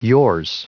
Prononciation du mot yours en anglais (fichier audio)
Prononciation du mot : yours